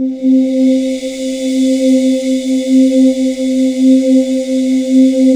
Index of /90_sSampleCDs/USB Soundscan vol.28 - Choir Acoustic & Synth [AKAI] 1CD/Partition C/07-DEEEP